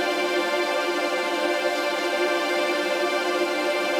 GS_TremString-F6+9.wav